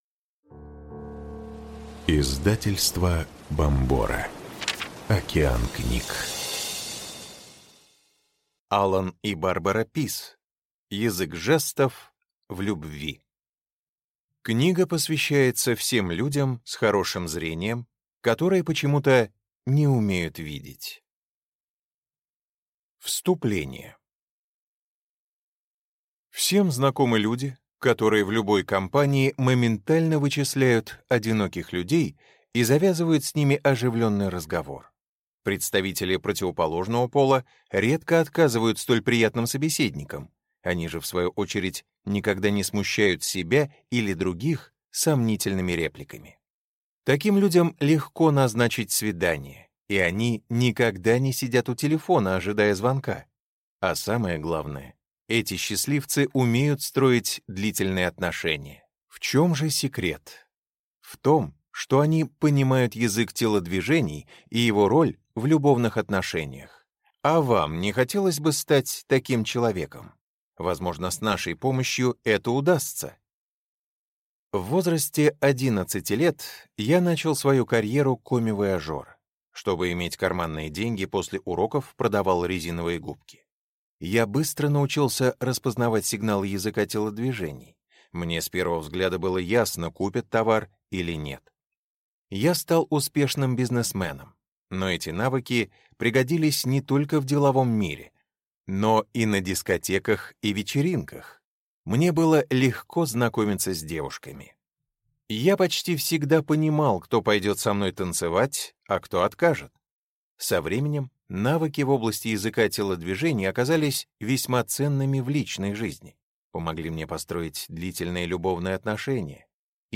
Аудиокнига Язык жестов в любви | Библиотека аудиокниг